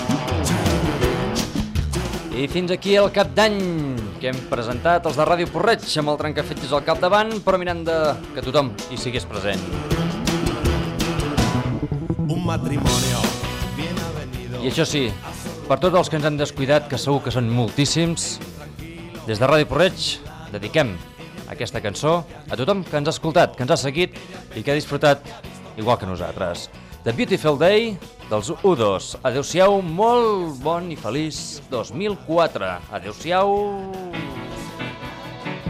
Comiat del programa i tema musical
Entreteniment